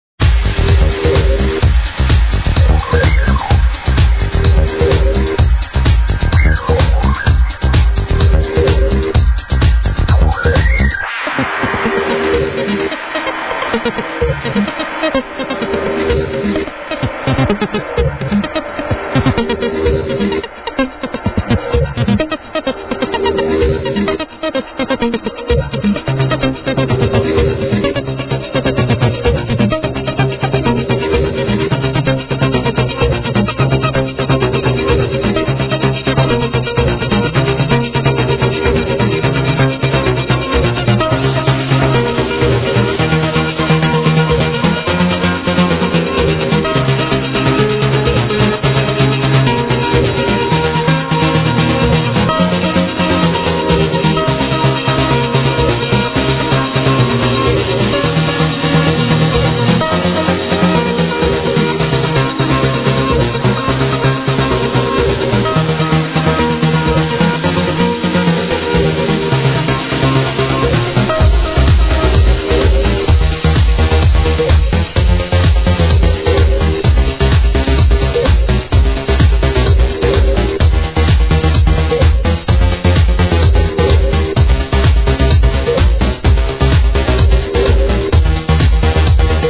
(Birthday Set)